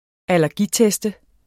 Udtale [ alæʁˈgi- ]